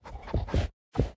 snapshot / assets / minecraft / sounds / mob / sniffer / searching1.ogg
searching1.ogg